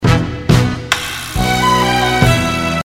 昨日、演歌の「ぎ〜」って音は何だ？と書いたが、あれはビブラスラップという楽器らしい（掲示板より）。
演歌なんかのイントロやフィルインで、よく「ぎ〜」っていう感じの音がよく使われていたりするが、先日ふと耳にして気になった。